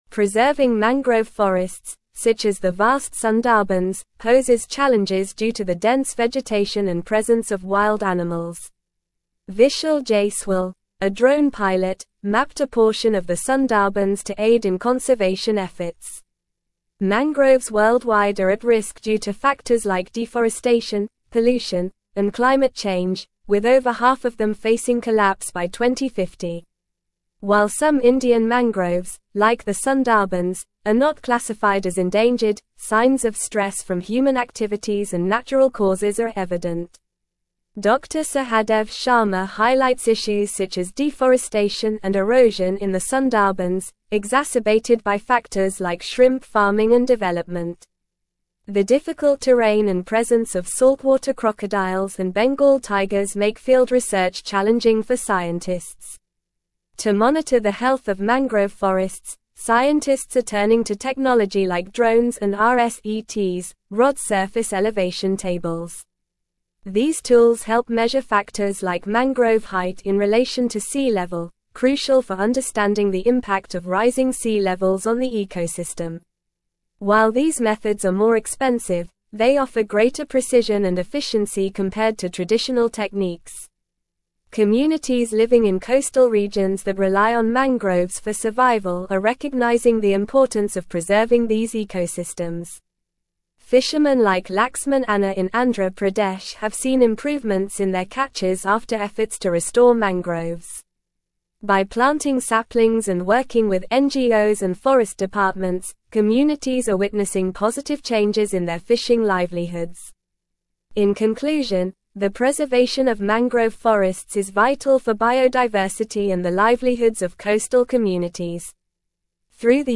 Normal
English-Newsroom-Advanced-NORMAL-Reading-Mapping-Sundarbans-Drones-Preserve-Worlds-Largest-Mangrove-Forest.mp3